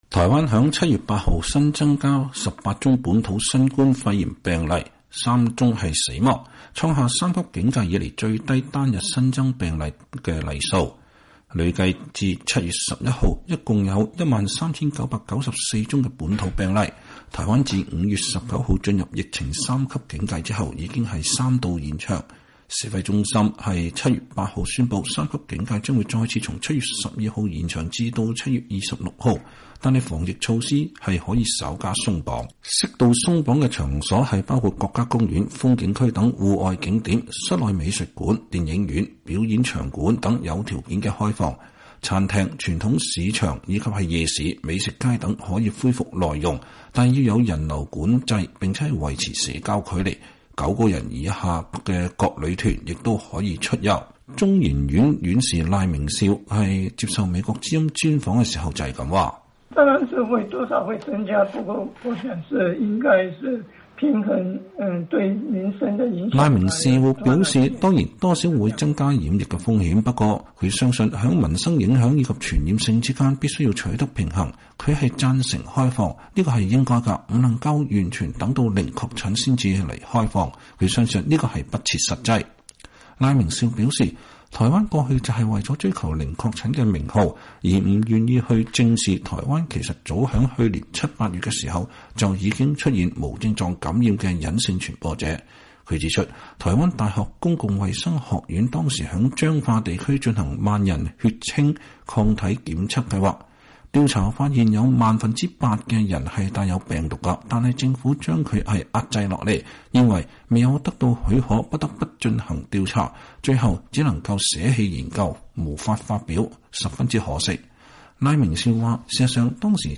台灣中央流行疫情指揮中心日前宣布，台灣三級警戒將延長至7月26日，但自13日起“微解封”，適度開放餐飲內用和戶外風景遊樂區等。有“台灣冠狀病毒之父”稱號的中央研究院院士賴明詔在接受美國之音專訪時表示，支持指揮中心“微解封”的決定，防疫跟民生必需取得平衡，如果糾結在零確診的數字上才開放，反而不切實際。